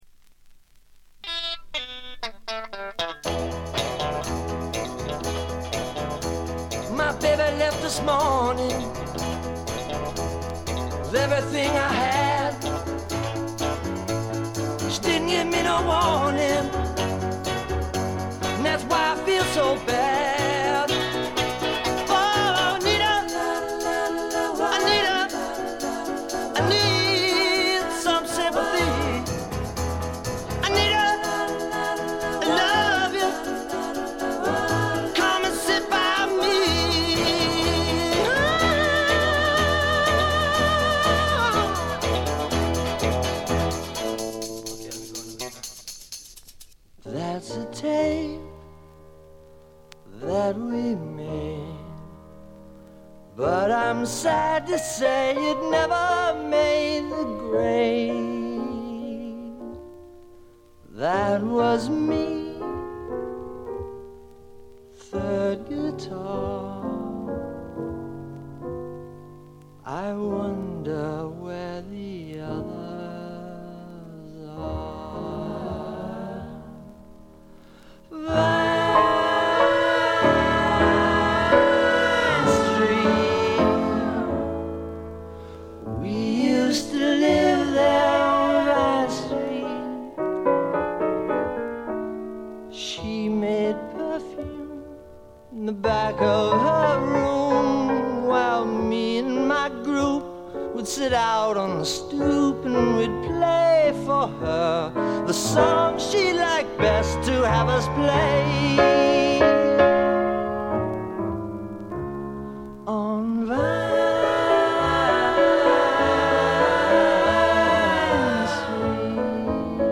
軽微なバックグラウンドノイズ程度。
試聴曲は現品からの取り込み音源です。